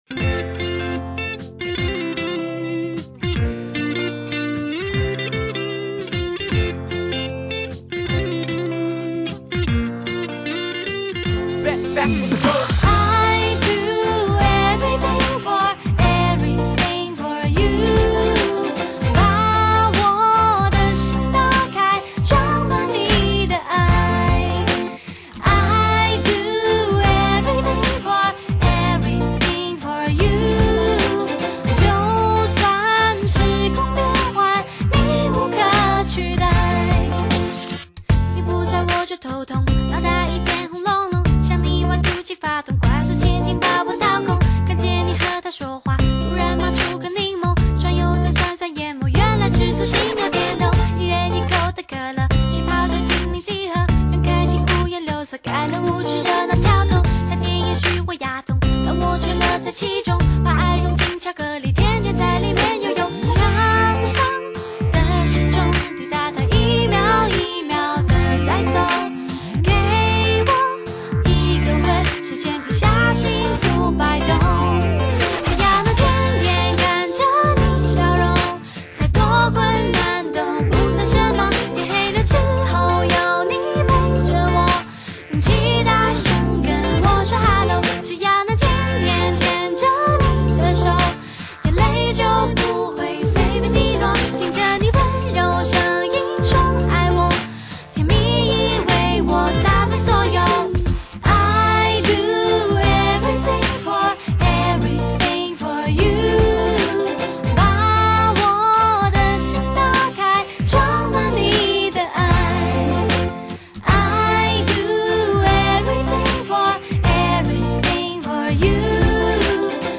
温柔嗓音